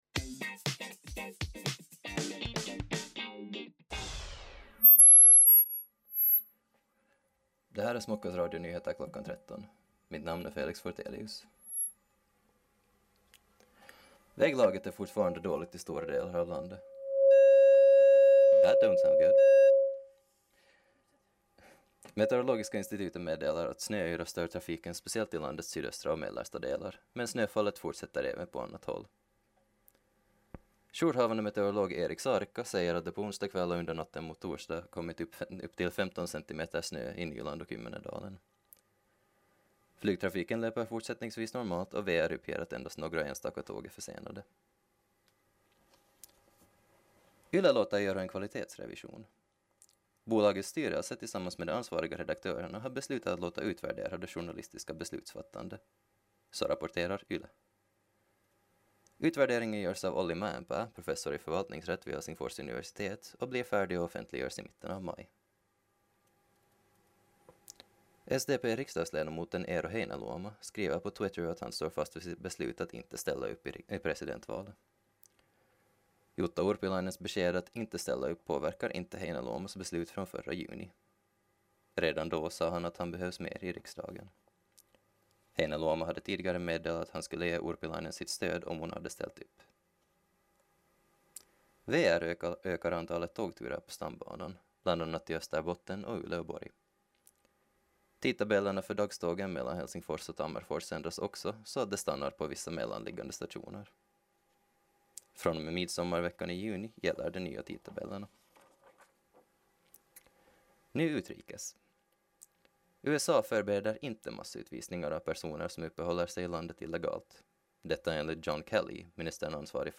Radionyheter kl. 13